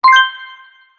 Success.wav